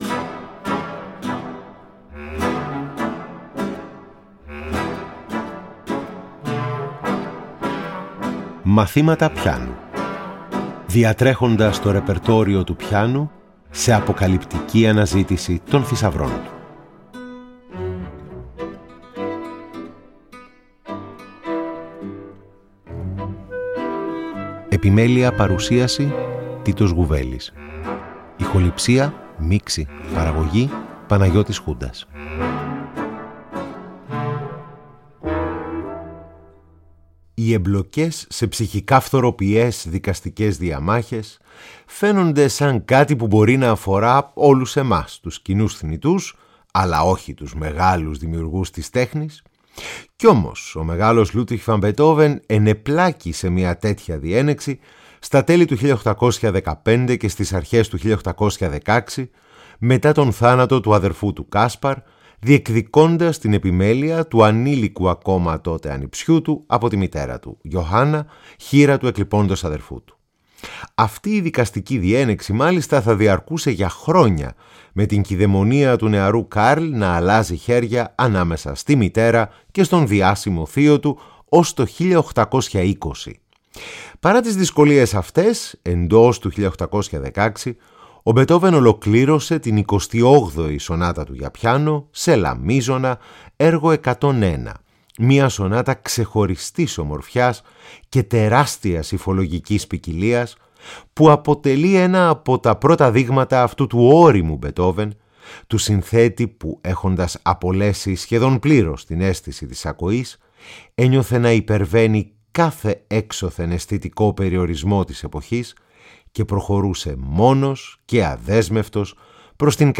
Εγχείρημα το οποίο ενισχύει η ενίοτε ζωντανή ερμηνεία χαρακτηριστικών αποσπασμάτων κατά τη διάρκεια της εκπομπής.
Εργα για Πιανο